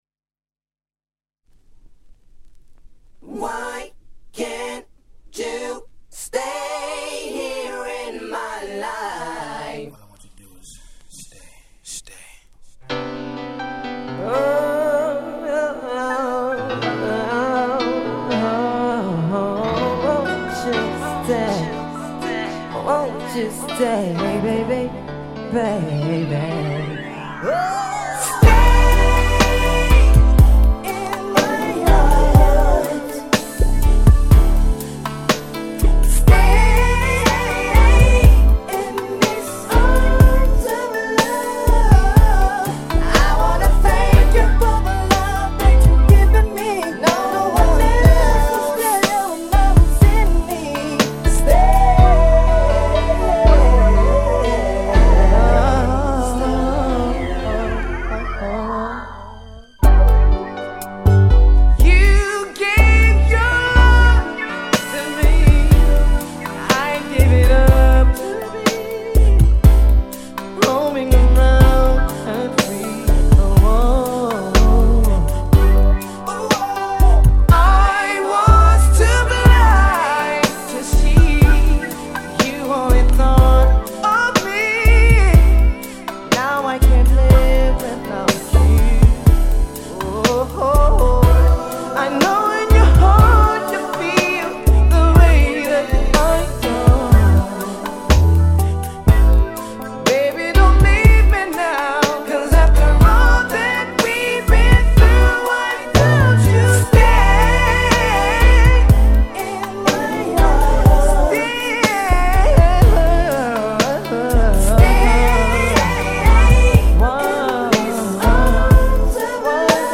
90's男性R&Bを中心としながら、女性陣、そして定番からマイナー物までちりばめ
90'sヴァイナルオンリー。
冬の澄んだ空気の夜にピッタリな心地良いスムースMixを是非。